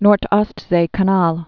(nört-ôstzā kä-näl)